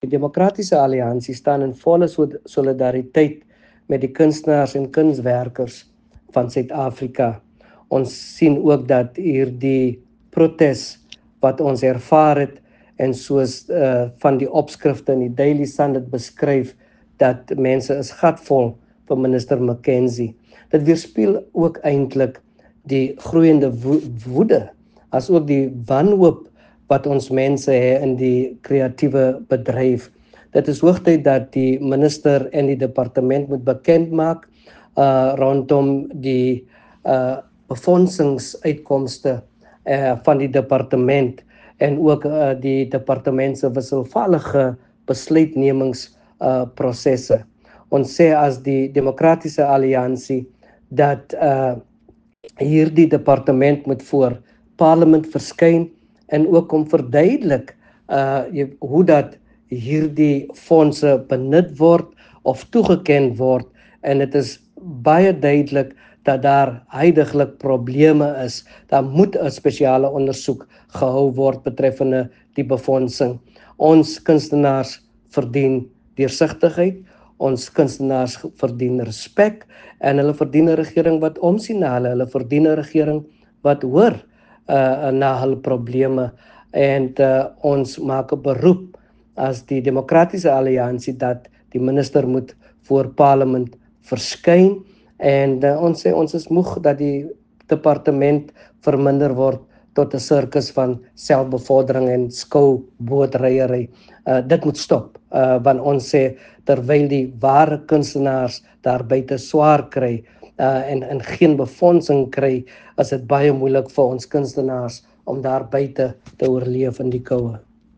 Afrikaans soundbite by Joe McGluwa MP.